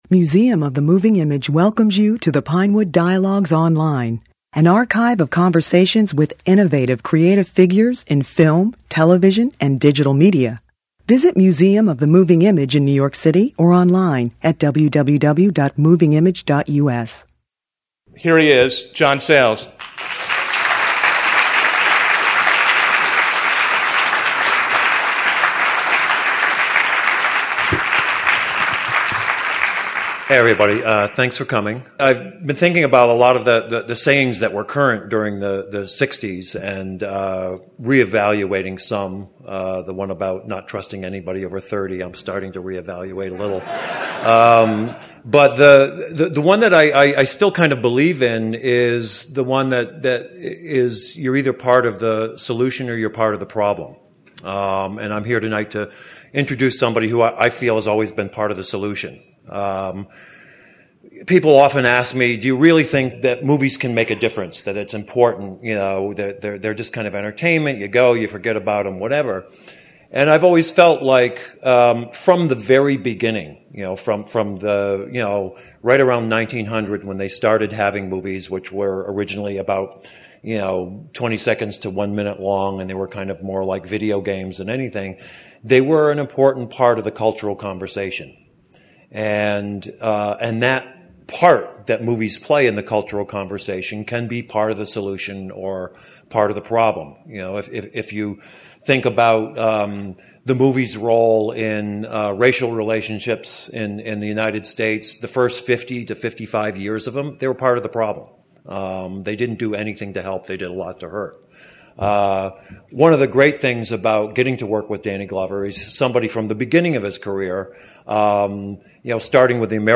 The Museum presented a special evening with Glover, with opening remarks by John Sayles, a conversation with Glover and Sayles (pictured), and two songs performed live by the Texas blues guitarist Gary Clark Jr., who makes his film debut in